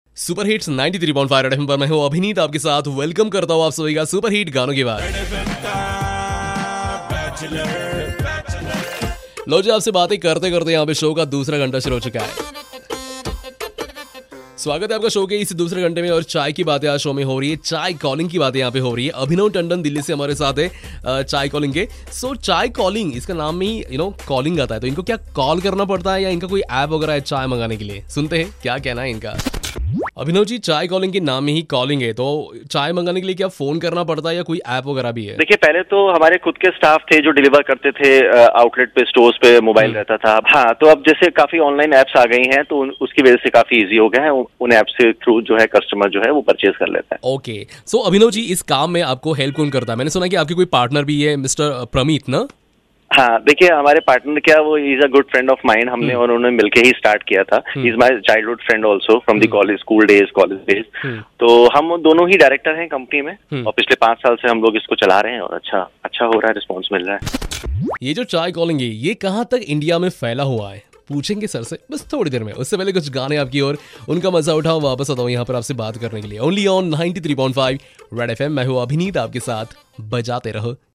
taking an interview